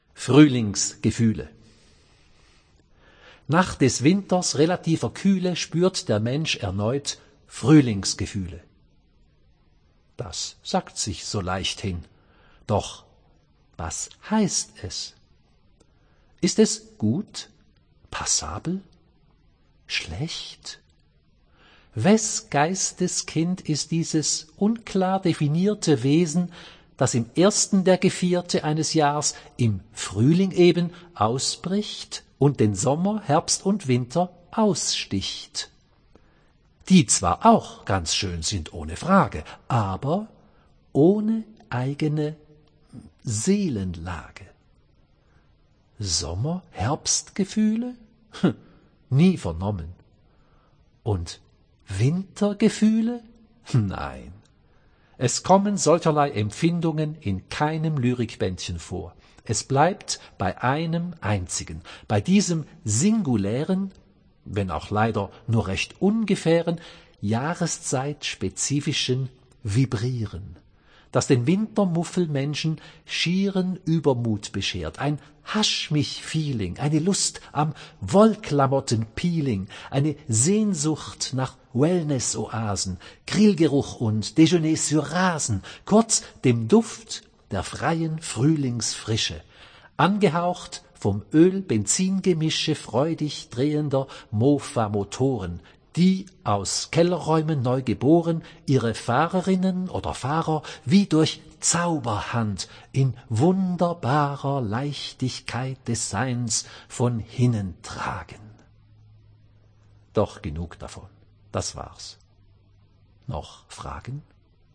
Autorenlesung hören
Audio_Gedicht_Fruehlingsgefuehle.mp3